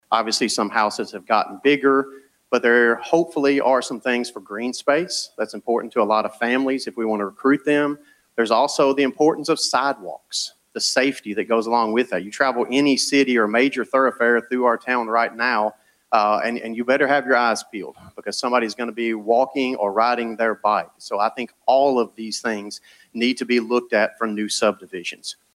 The League of Women Voters hosted the candidates Monday night for its final forum of the night.